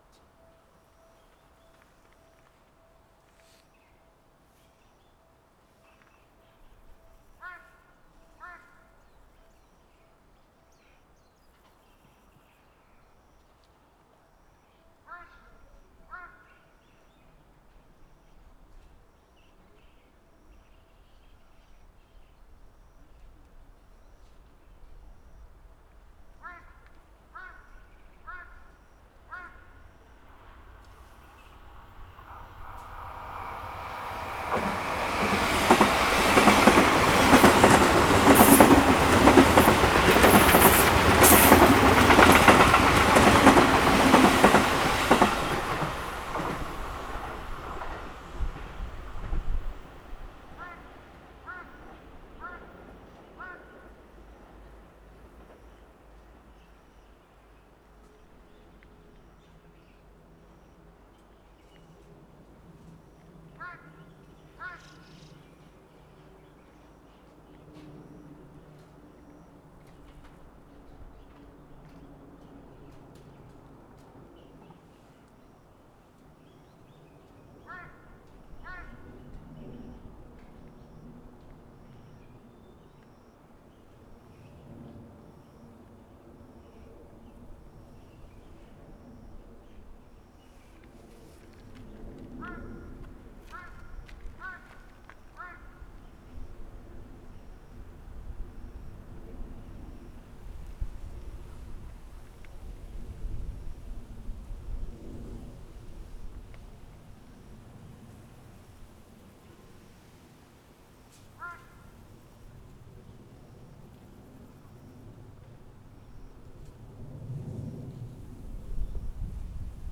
下り電車通過。
H2essential MS内蔵マイク指向性120°＋
ZOOM　ヘアリーウィンドスクリーン WSH-2e